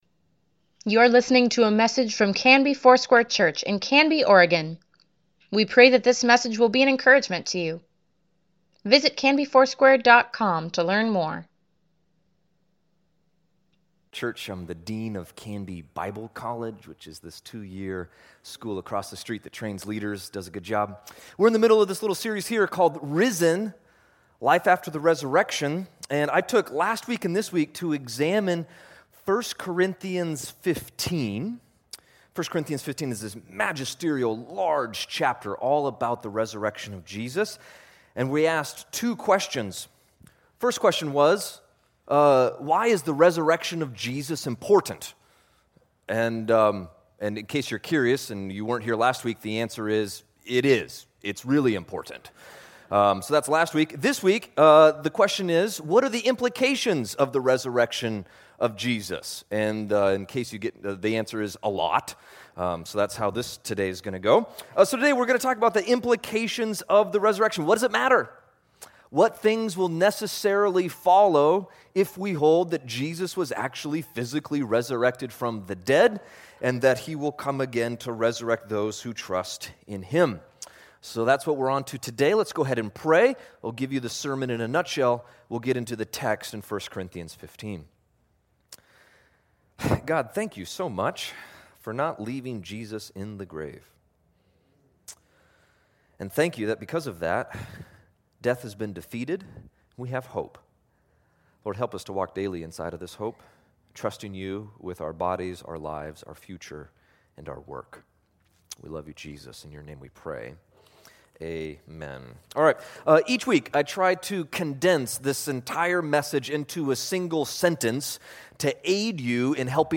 Weekly Email Water Baptism Prayer Events Sermons Give Care for Carus Risen: Life After the Resurrection (7) June 3, 2018